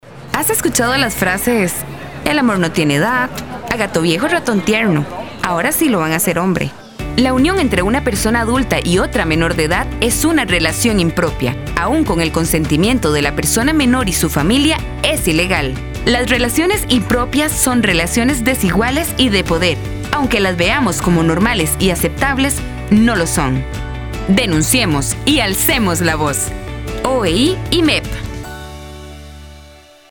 Audio de la campaña radial de comunicación: Alcemos la voz contra el hostigamiento. Versión: no es normal. Público meta: todo público